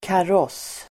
Ladda ner uttalet
Uttal: [kar'ås:]